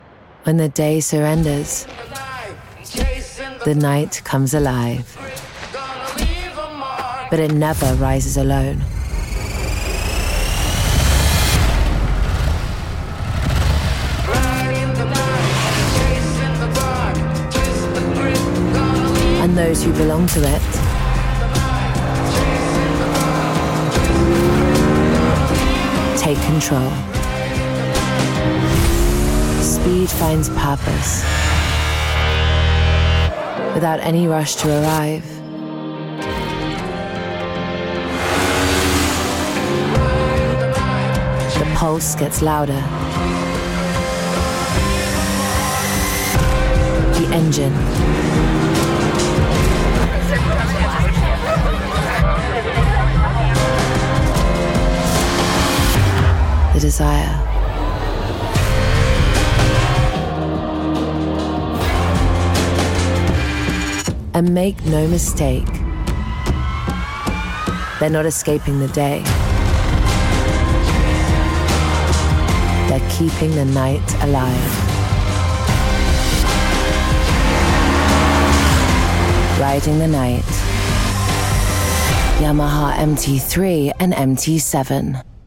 Notino Fashion Commercial